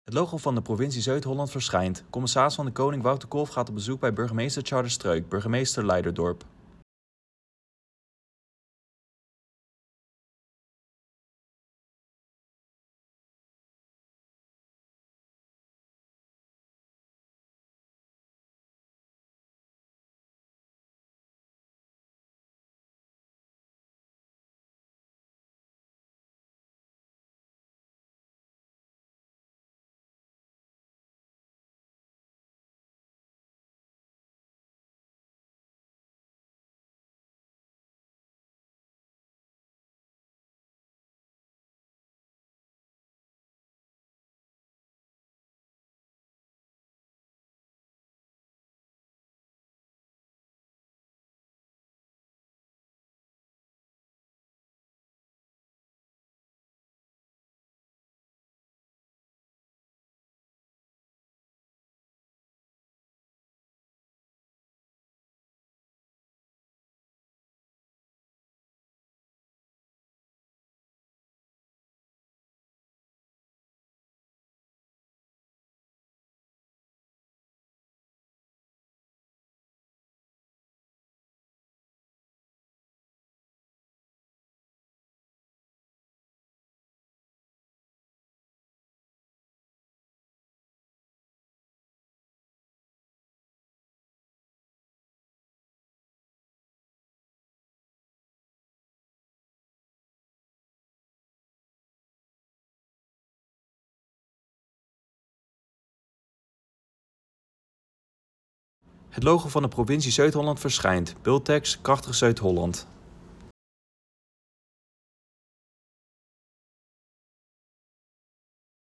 CdK in gesprek met burgemeester Leiderdorp
De commissaris van de Koning bezoekt de komende tijd alle 50 gemeenten van Zuid-Holland. In deze video gaat hij in gesprek met de burgemeester van Leiderdorp